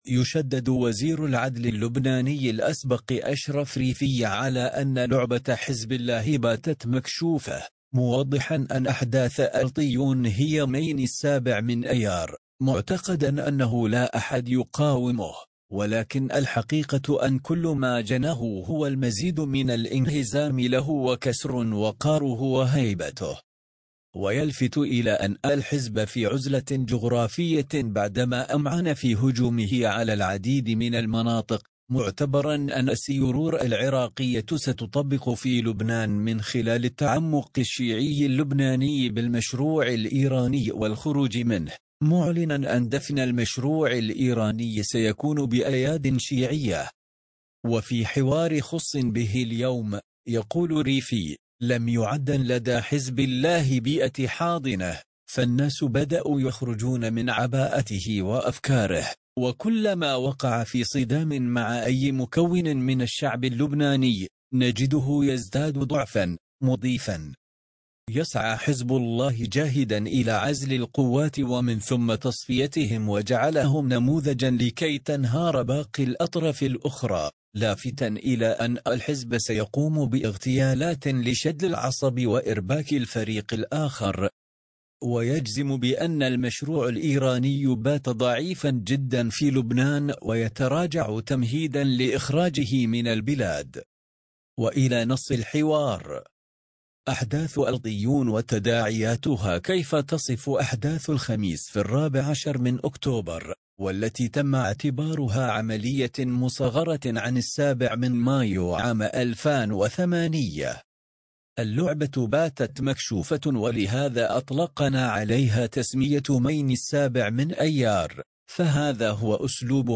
فيديو وبالنص وبالصوت/مقابلات جريئة ووطنية وسيادية مع اللواء اشرف ريفي يفضح من خلالها مخطط حزب الله لإحتلال عين الرمانة والإستفراد بجعجع قضائياً بتركيب ملف له